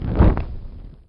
torchon3.wav